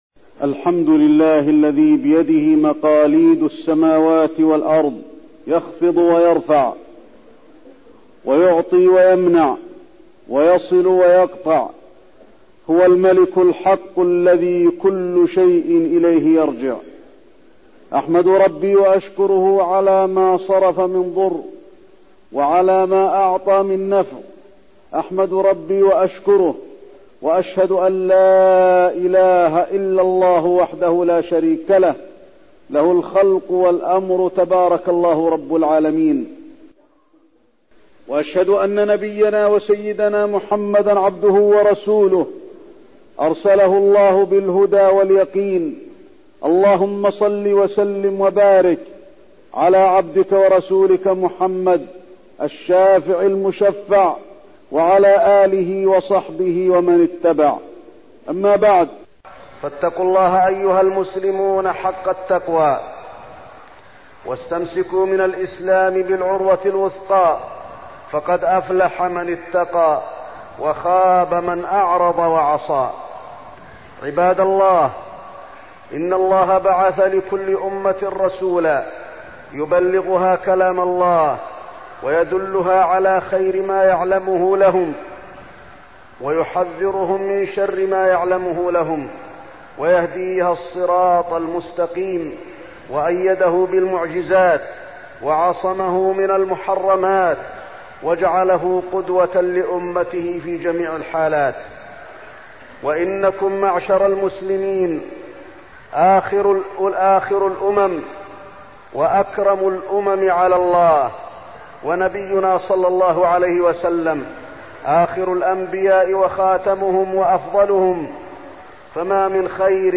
تاريخ النشر ٣٠ رجب ١٤١٦ هـ المكان: المسجد النبوي الشيخ: فضيلة الشيخ د. علي بن عبدالرحمن الحذيفي فضيلة الشيخ د. علي بن عبدالرحمن الحذيفي الإسراء والمعراج